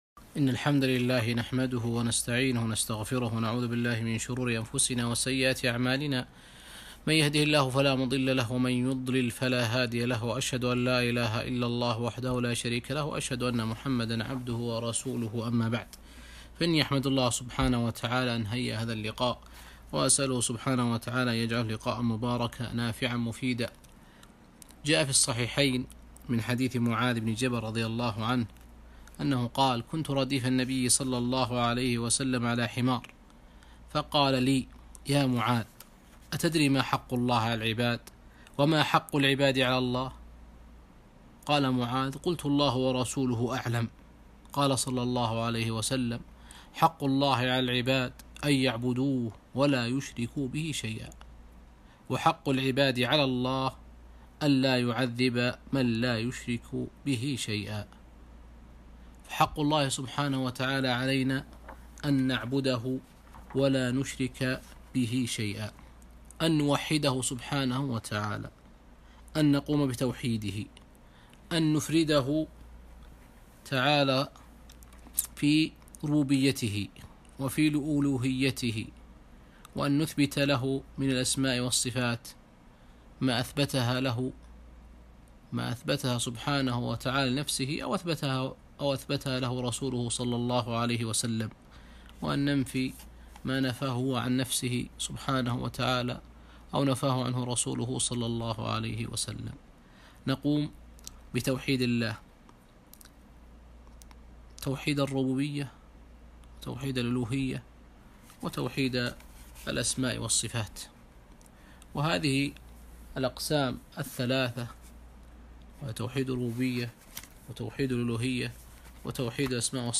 محاضرة - التوحيد أنواعه وفضله